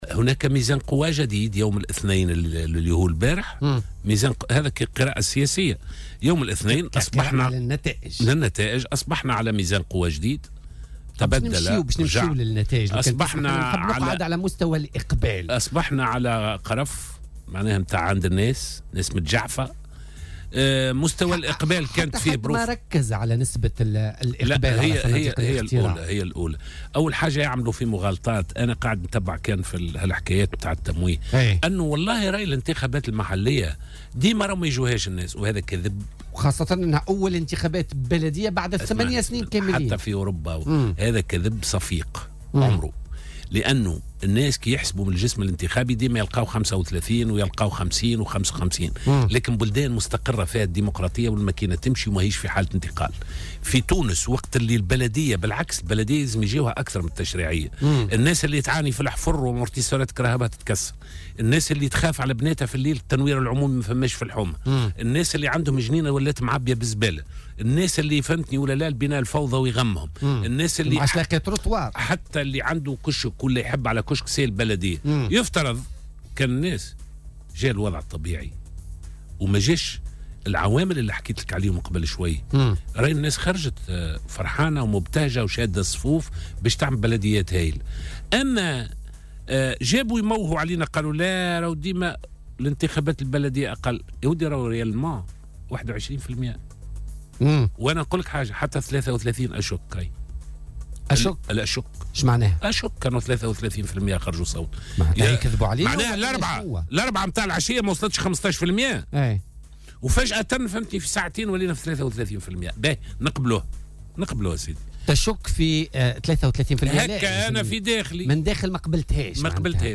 وقال في مداخلة له اليوم في برنامج "بوليتيكا" إن هذه الانتخابات أسفرت وفقا للنتائج الأولية عن ميزان قوى جديد، وفق تعبيره.